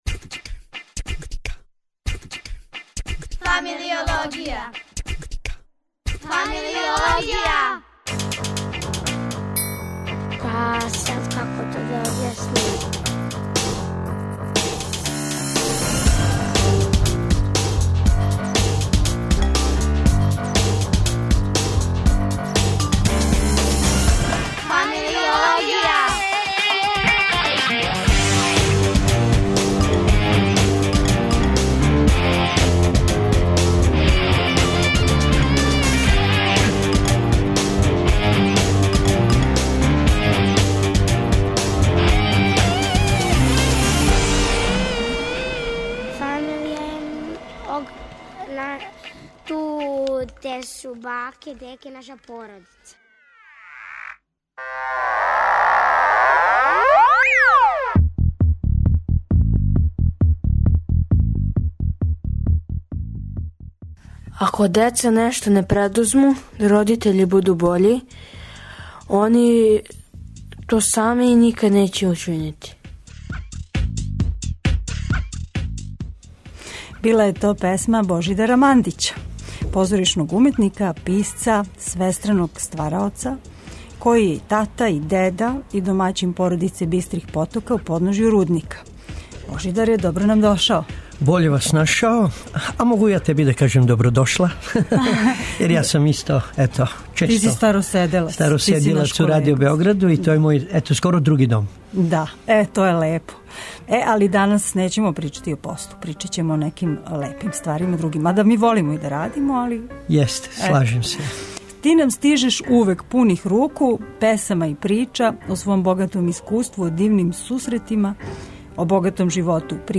Породична прича се, овог пута, претворила у песме о игри, слободи, детињству...